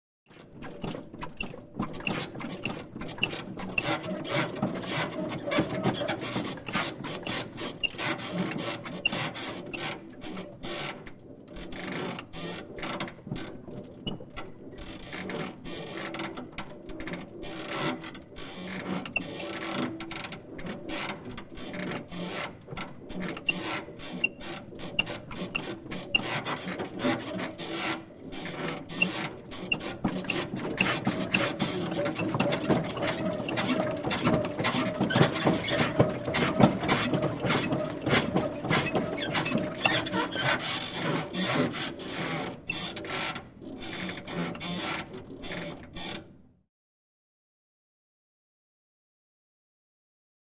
Suspension Bounces; With Creaks, Squeaks And Movement.